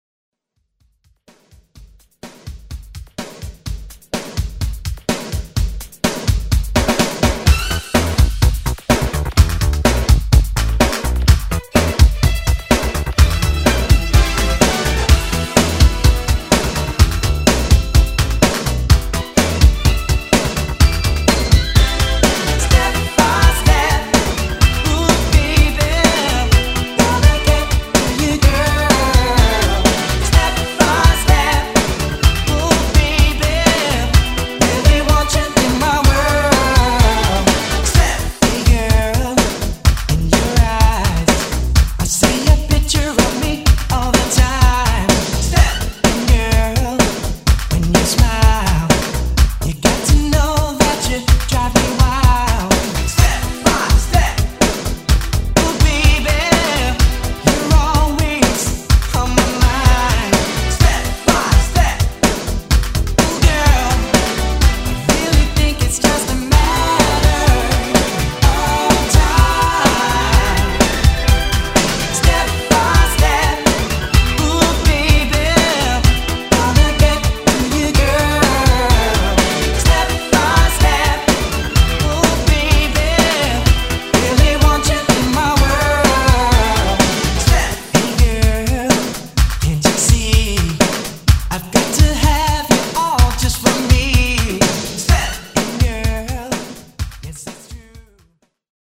Genre: OLD SCHOOL HIPHOP Version: Clean BPM: 95 Time